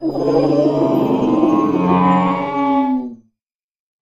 Cri de Balbalèze dans Pokémon HOME.